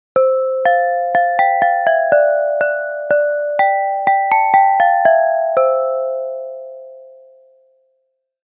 Notification Sounds / Sound Effects 22 Dec, 2025 Christmas UI Notification Melody Read more & Download...
Festive-notification-melody-sound-effect.mp3